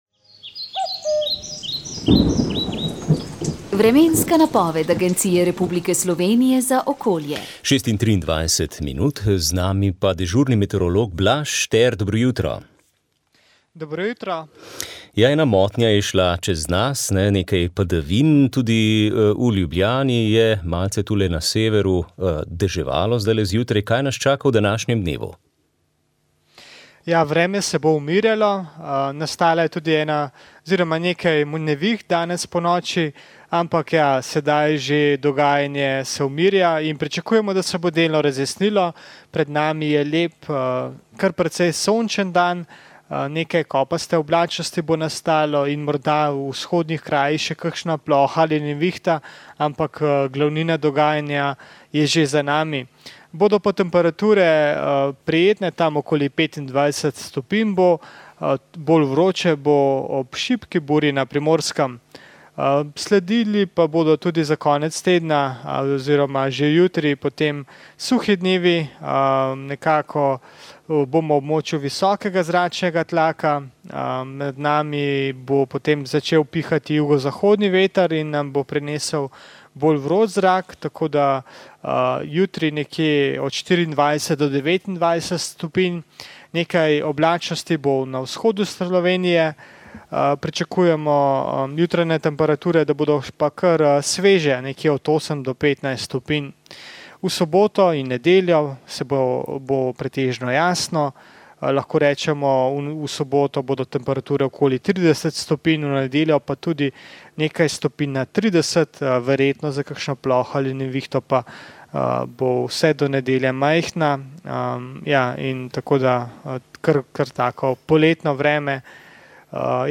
Vremenska napoved